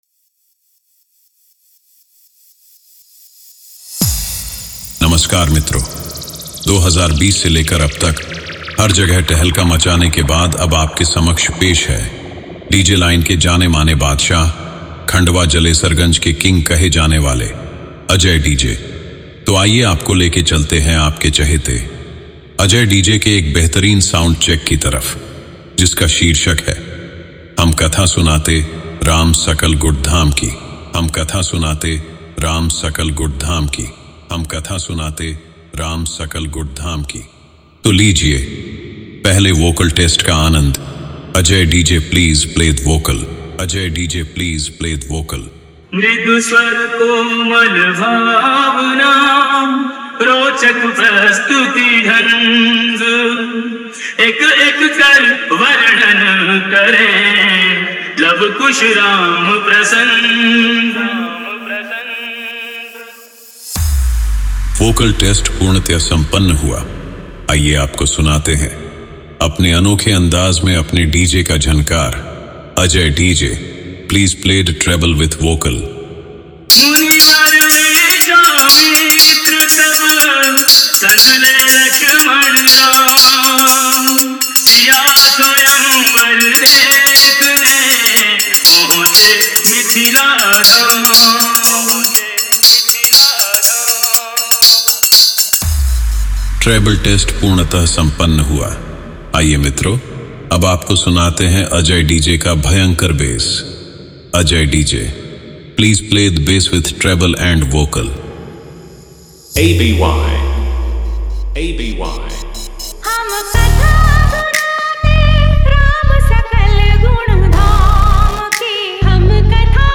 Bolbam 2025 Dj Songs Report This Song Play Pause Vol + Vol -